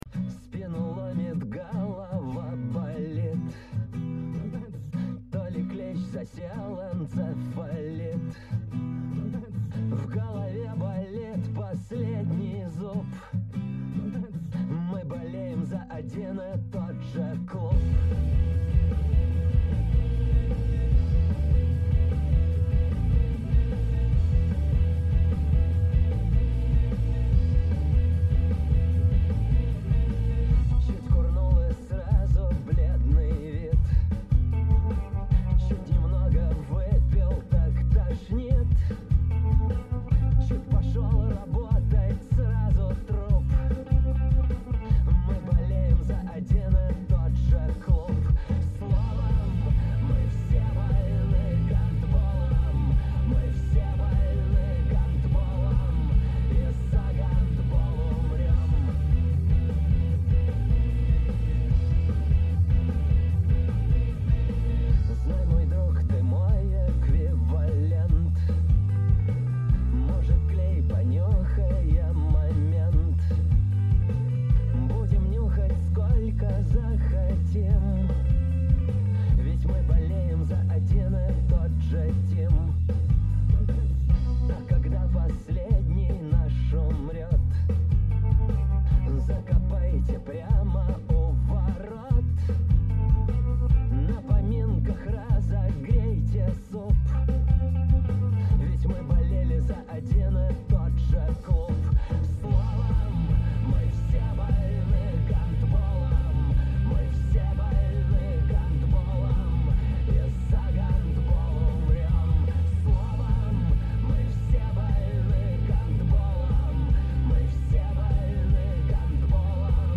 Качество:Задавка